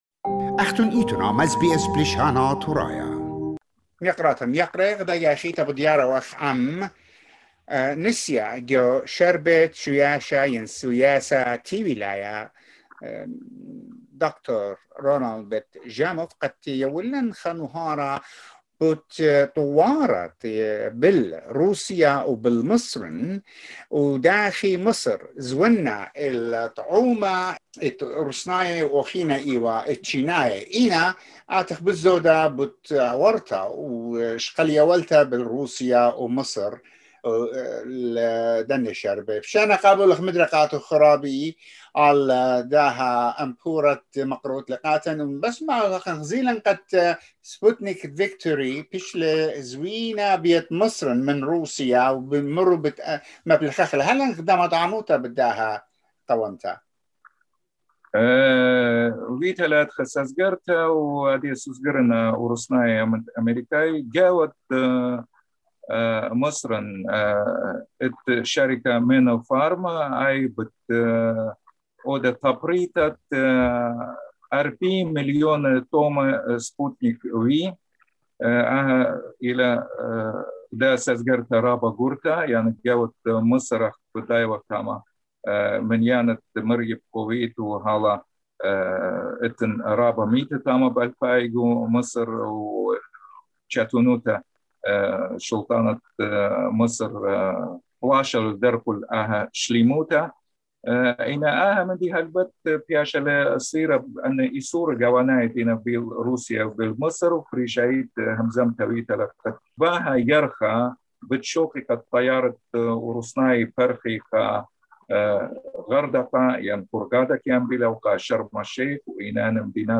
The latest international political and economical developments reported to you by our correspondent from Moscow
SBS Assyrian